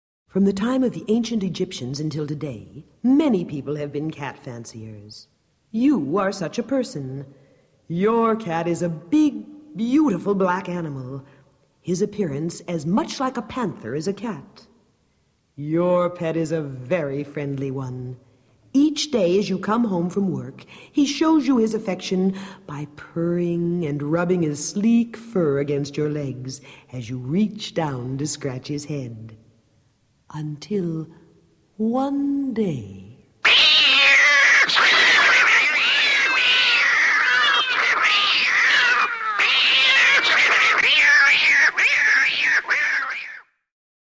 Index of /Sounds/Halloween/Haunted House-64
05 Your Pet Cat.mp3